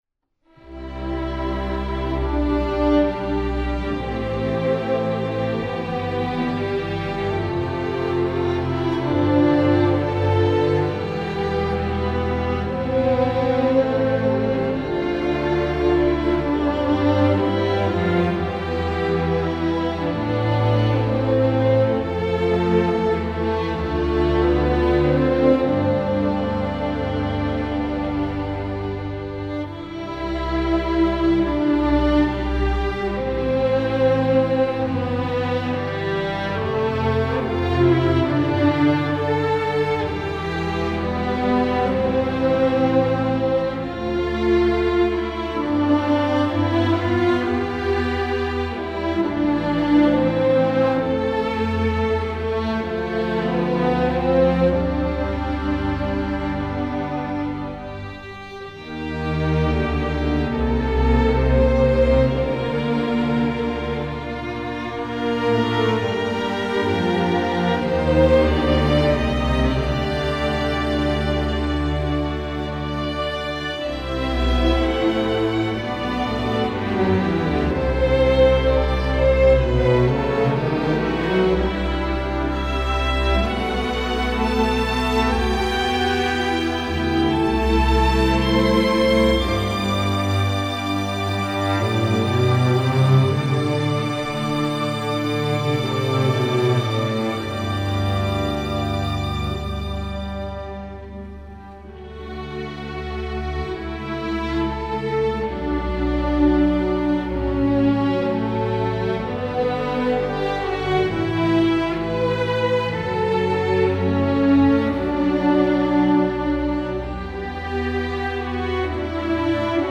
Instrumentation: string orchestra (full score)